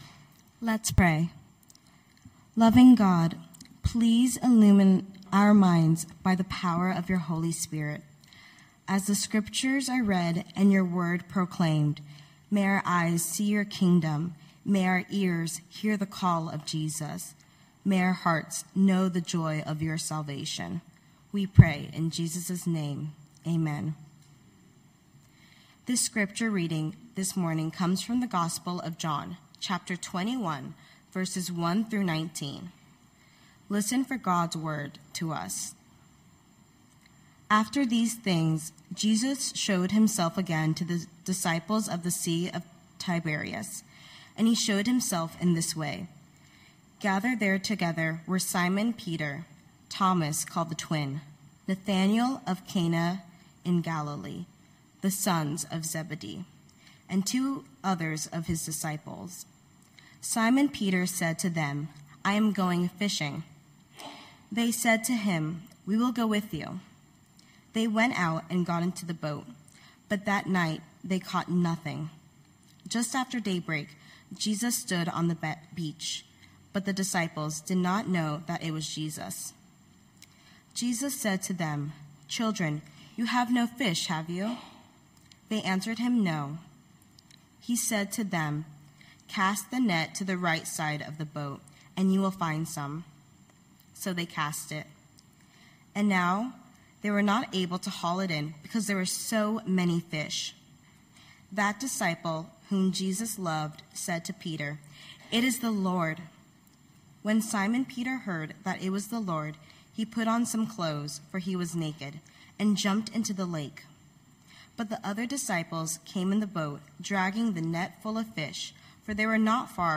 Knox Pasadena Sermons Redeeming Our Regrets Jan 11 2026 | 00:29:21 1x 00:00 / 00:29:21 Subscribe Share Spotify RSS Feed Share Link Embed